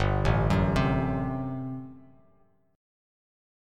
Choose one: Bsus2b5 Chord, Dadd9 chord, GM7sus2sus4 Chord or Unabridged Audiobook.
GM7sus2sus4 Chord